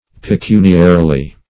pecuniarily - definition of pecuniarily - synonyms, pronunciation, spelling from Free Dictionary Search Result for " pecuniarily" : The Collaborative International Dictionary of English v.0.48: Pecuniarily \Pe*cun"ia*ri*ly\, adv. In a pecuniary manner; as regards money.
pecuniarily.mp3